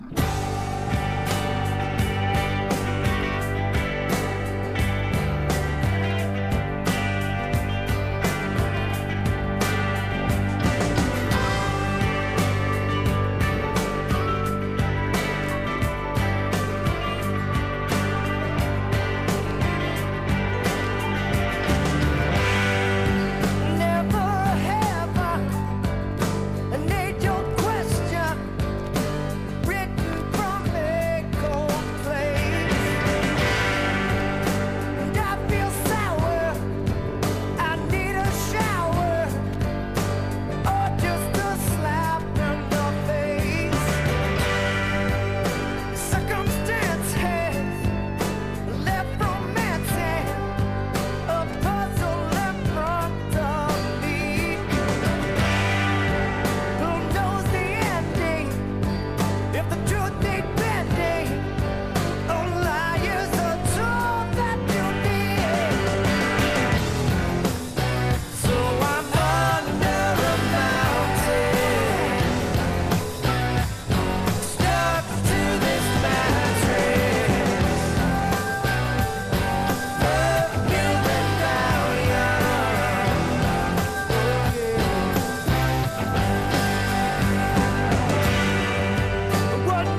1. 90'S ROCK >
飾り気のないシンプルな乾いたサウンドはやはり素晴らしいです。
ALTERNATIVE / GRUNGE